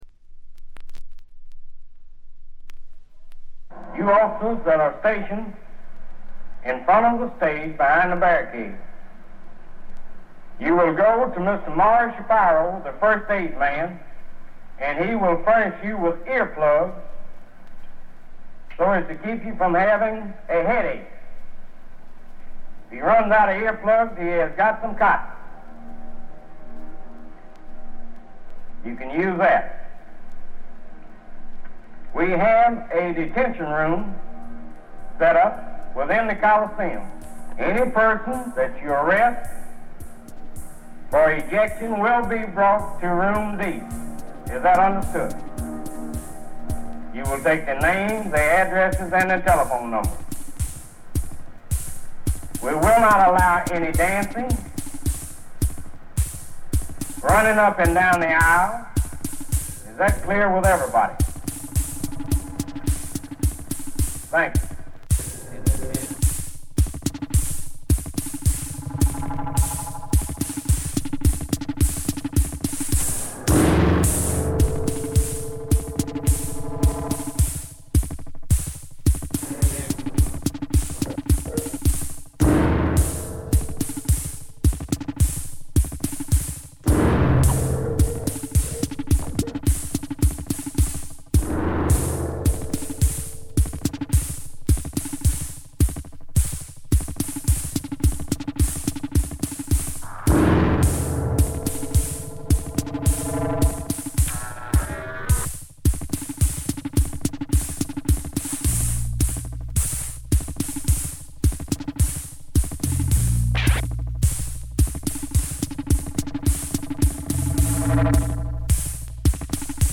試聴曲は現品からの取り込み音源です。
Electronics, Tape
Drums